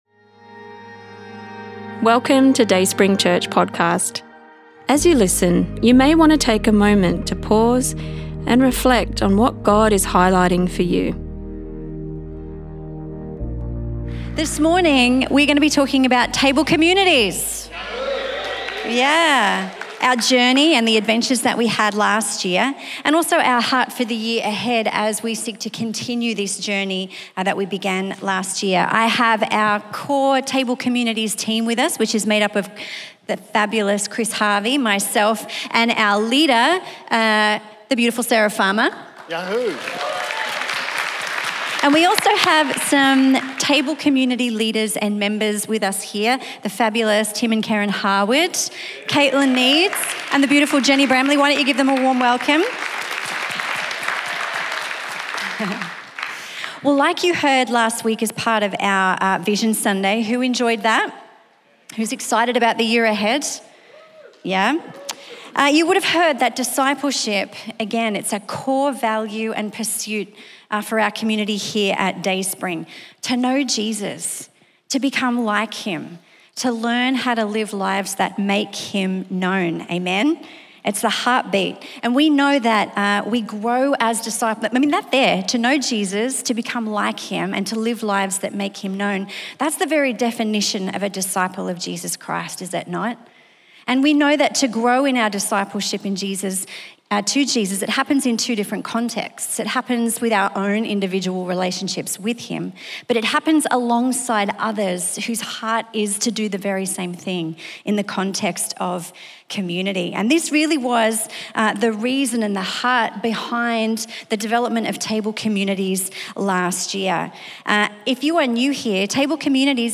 Table Community Panel